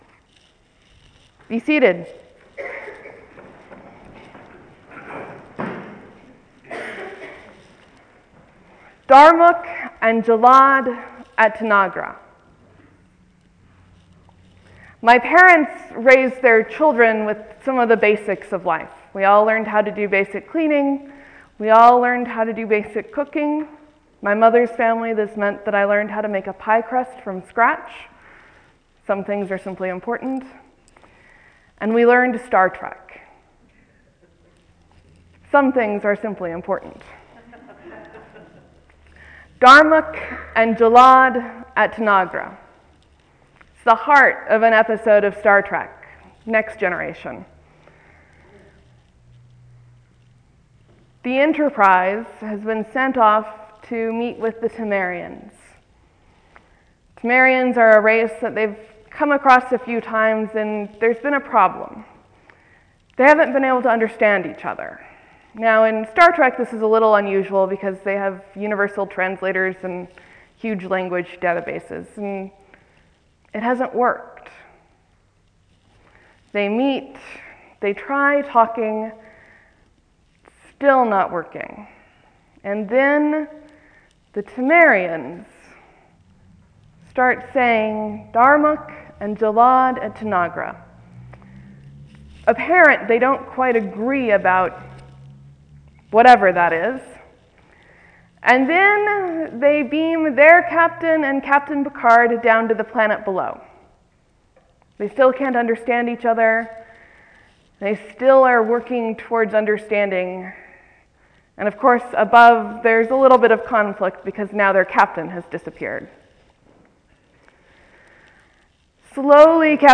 Sermon, , , , 2 Comments